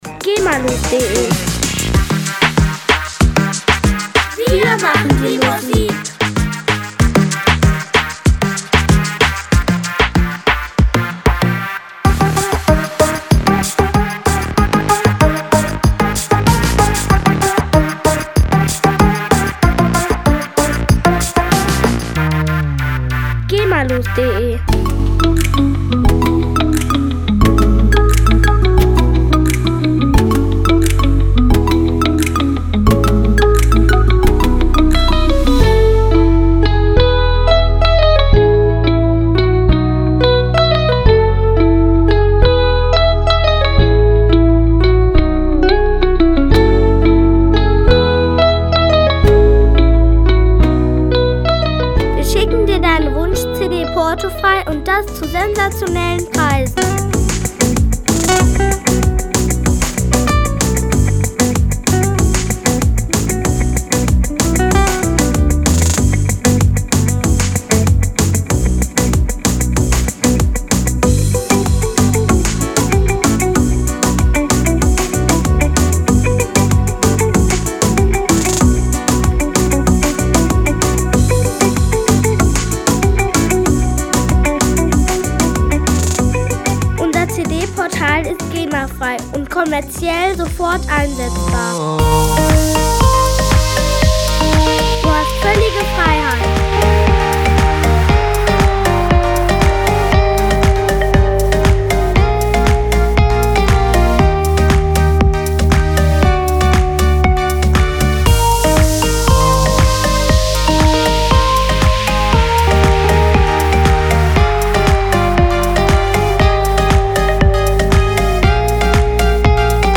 Musikstil: Reggaeton